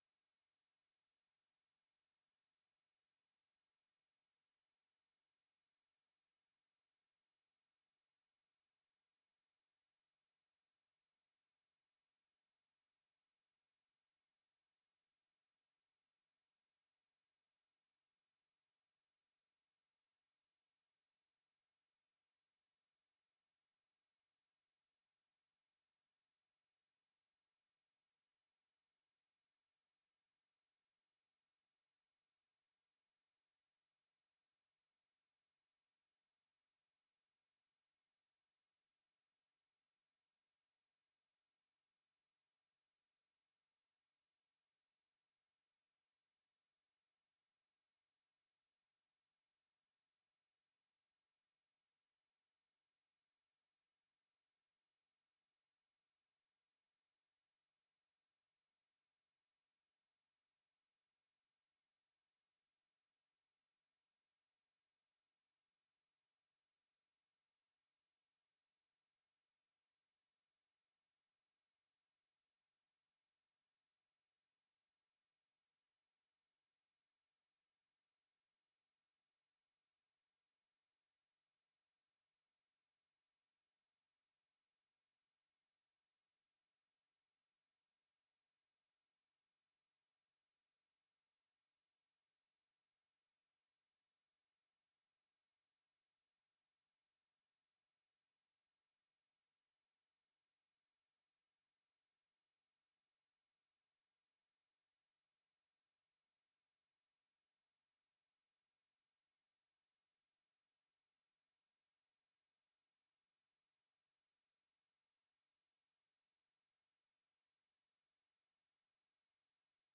Conversation with MCGEORGE BUNDY, October 27, 1964
Secret White House Tapes